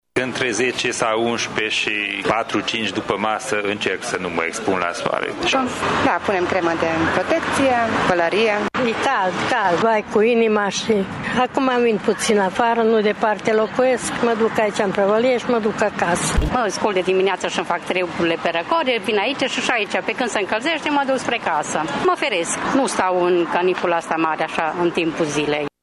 Tg. mureșenii încearcă să țină cont de sfaturile medicilor: